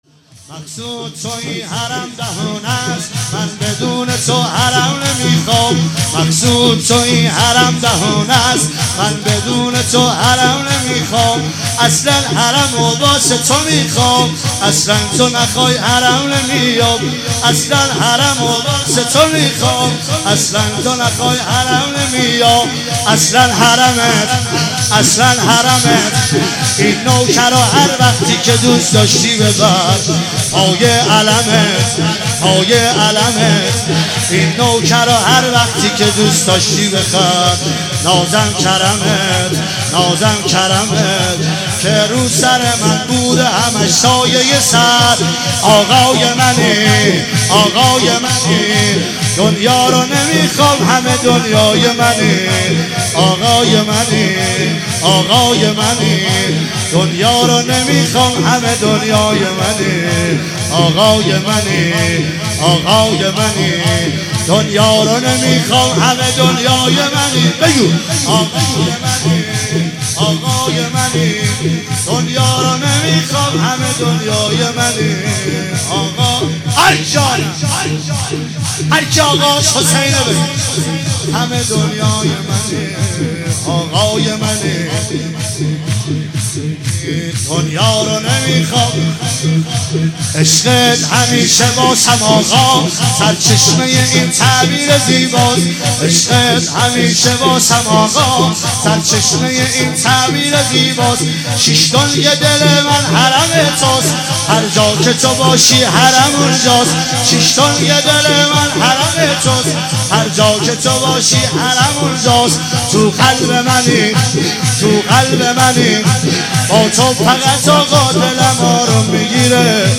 مراسم شب ۲۲ محرم ۱۳۹۶
هیئت حضرت ابوالفضل سرسنگ کاشان
شور